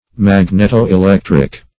Magneto-electric \Mag`net*o-e*lec"tric\, Magneto-electrical